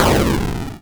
bazooka.wav